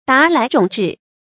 沓來踵至 注音： ㄊㄚˋ ㄌㄞˊ ㄓㄨㄙˇ ㄓㄧˋ 讀音讀法： 意思解釋： 指紛紛到來。